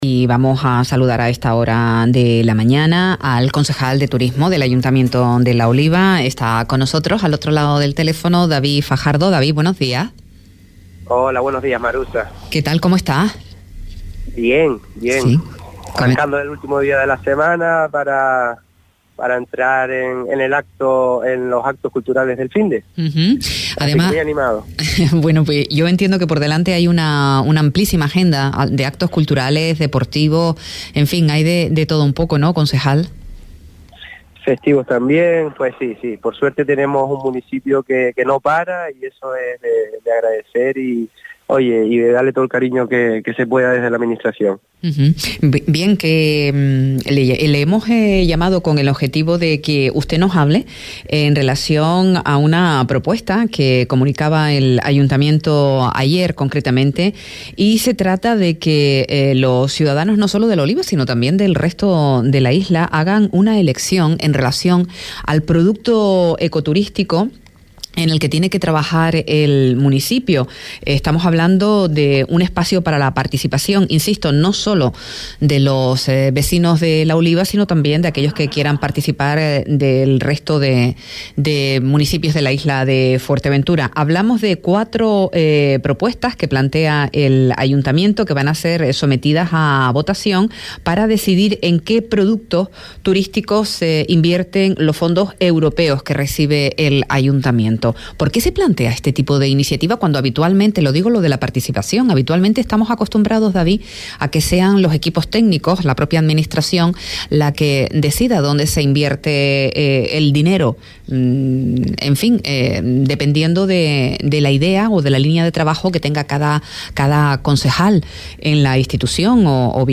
A Primera Hora entrevista a David Fajardo, concejal de Turismo en La Oliva para comentar la iniciativa donde se invita a participar a los vecinos.
Entrevistas